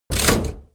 lever.ogg